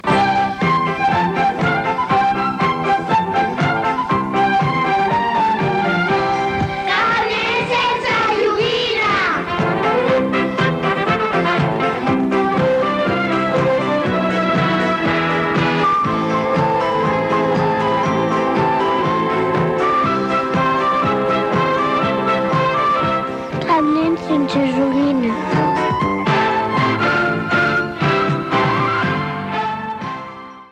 Sintonia del programa amb identificació.